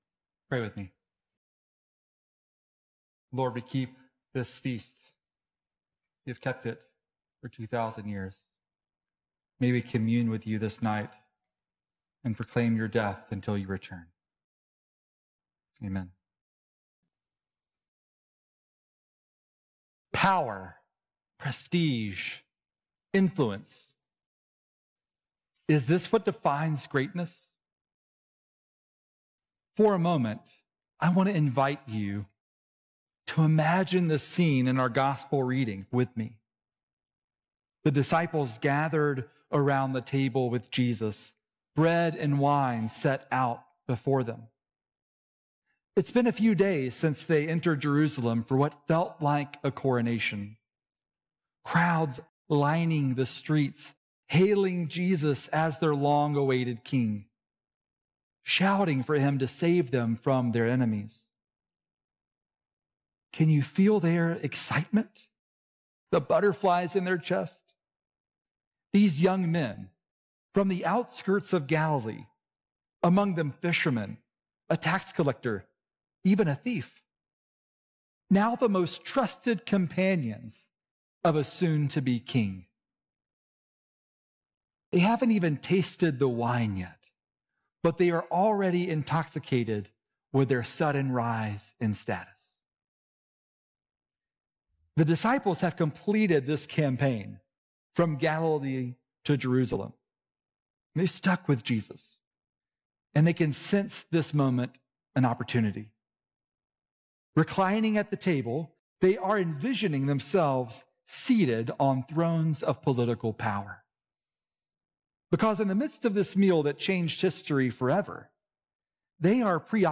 Sermons - Holy Cross Anglican Cathedral